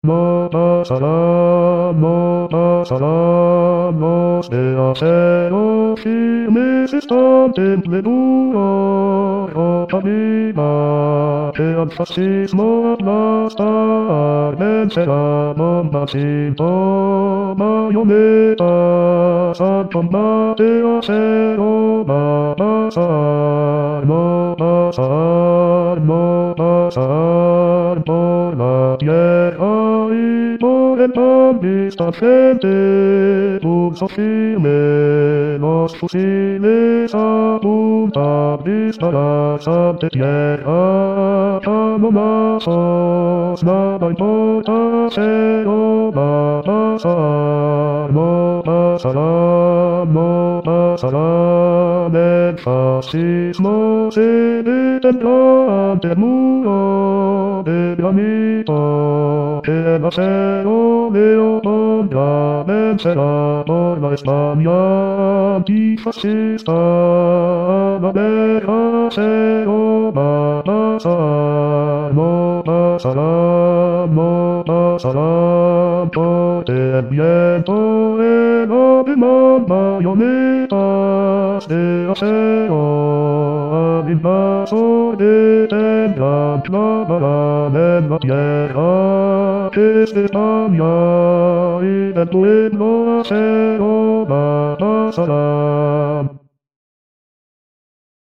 Mp3 di studio
bassi